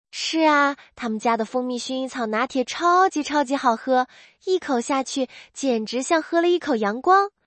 5、音色自然：该模型使用海量语音数据训练，可以根据原始语音内容自适应调节语气和表现力的拟人音色
描述：阳光积极、亲切自然小姐姐。